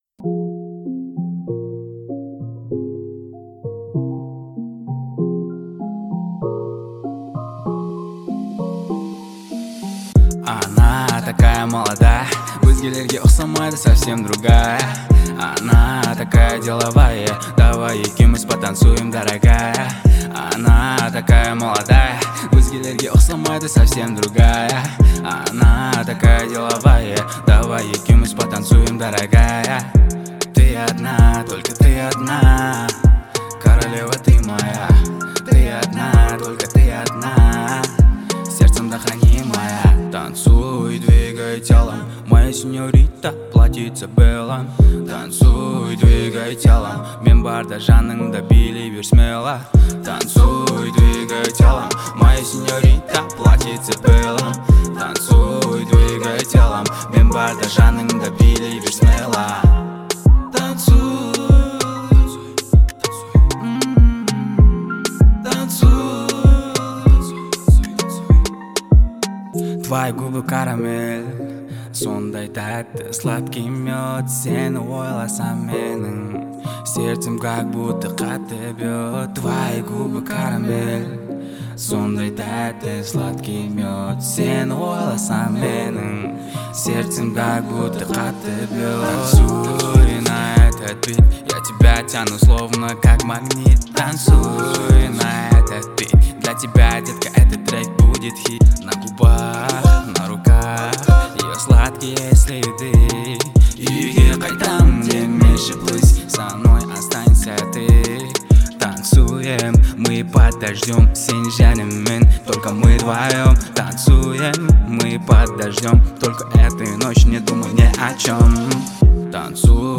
это яркий представитель жанра поп-рок
харизматичным вокалом и заразительными мелодиями